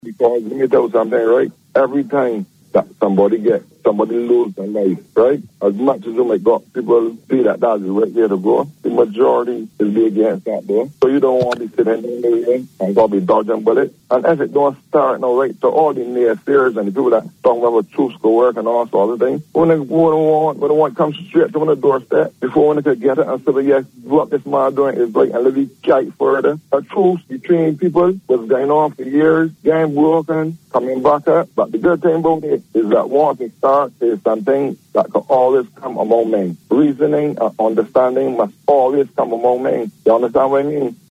He called into VOB’s Down to Brasstacks call-in program to respond to skepticism about the development.